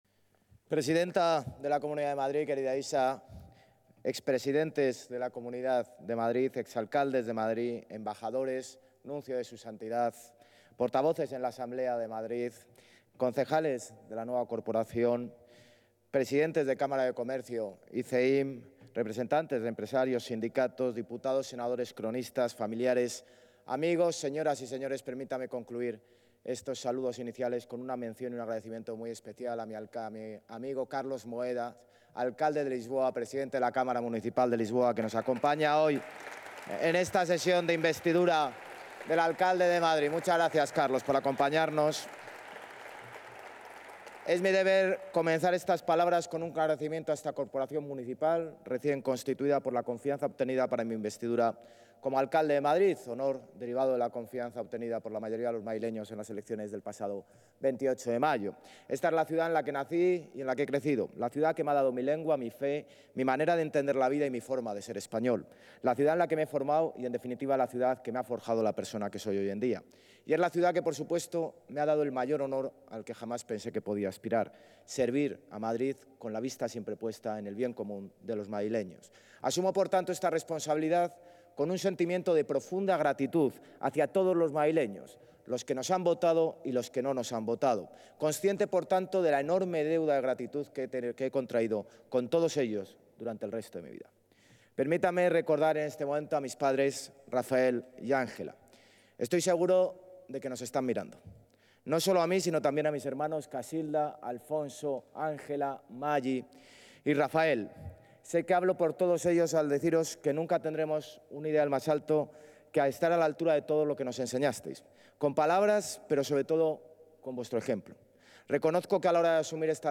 Nueva ventana:Intervención del alcalde de Madrid, José Luis Martínez-Almeida
(AUDIO) INTERVENCIÓN ALCALDE DE MADRID.mp3